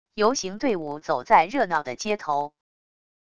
游行队伍走在热闹的街头wav音频